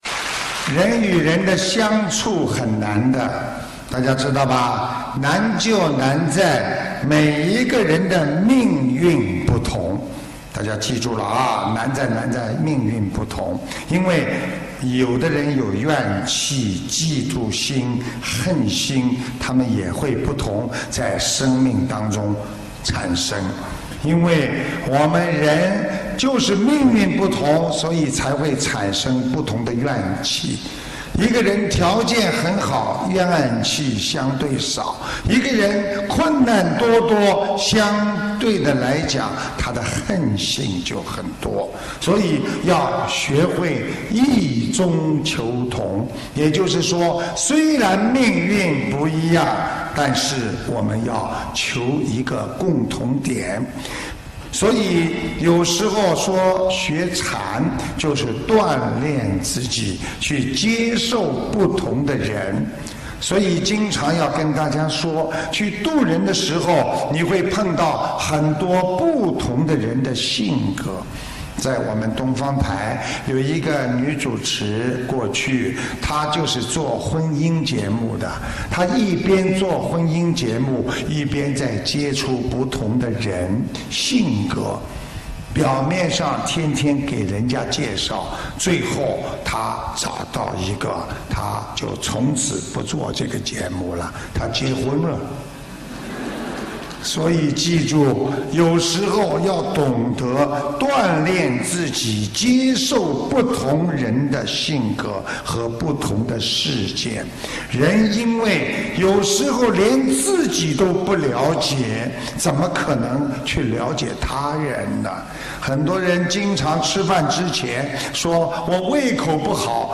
▶ 语 音 朗 读 点击进入 ☞ 首页 > 每日 畅听 平时你们难过、烦恼， 多听听师父 的录音 ， 会笑的。